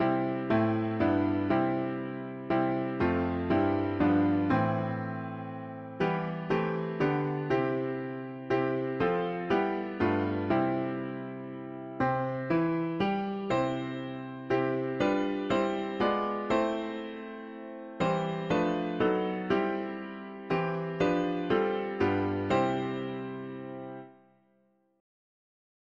Key: C major
Tags english theist 4part chords